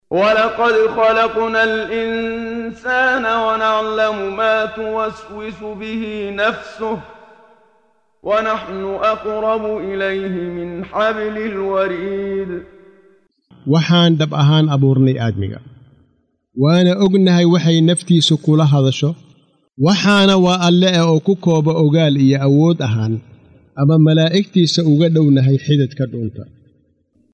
Waa Akhrin Codeed Af Soomaali ah ee Macaanida Suuradda Qaaf oo u kala Qaybsan Aayado ahaan ayna la Socoto Akhrinta Qaariga Sheekh Muxammad Siddiiq Al-Manshaawi.